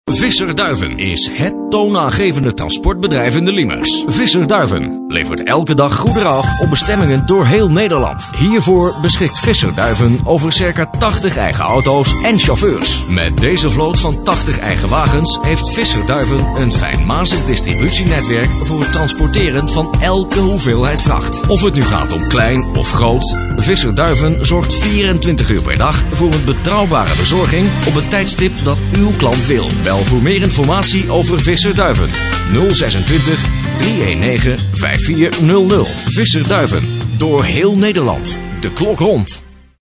Commercials
Op deze pagina tref je een aantal voorbeelden aan van commercials die de afgelopen tijd door mij zijn ingesproken voor diverse lokale-, regionale- en interrnet-radiostations.